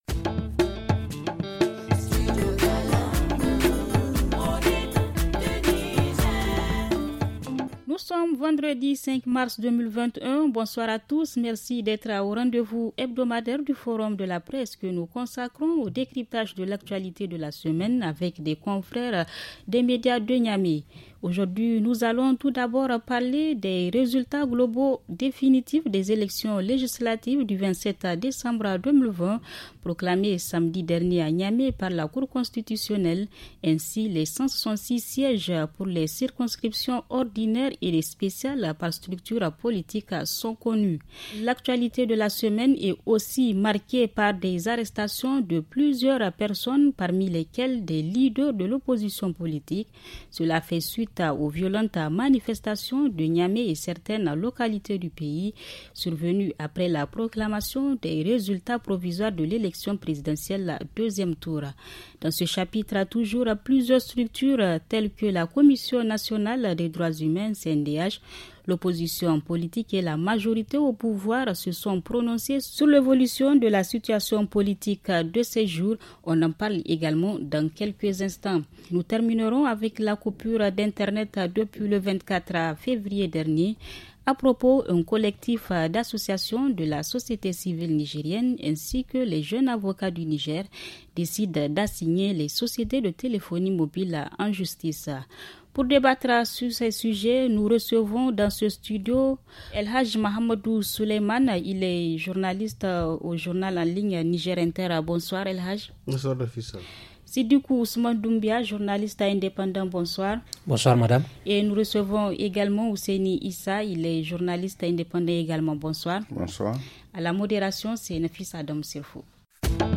Le rendez-vous de la presse de ce vendredi 05 mars 2021 - Studio Kalangou - Au rythme du Niger